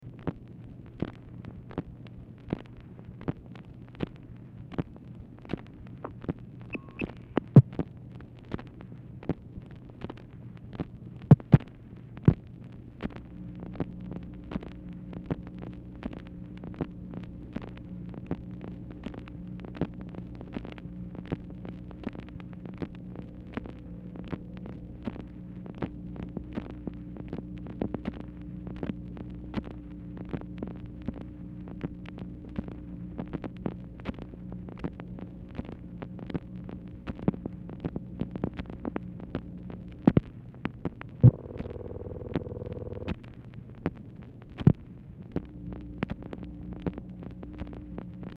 Format Dictation belt
White House Telephone Recordings and Transcripts Speaker 2 MACHINE NOISE Specific Item Type Telephone conversation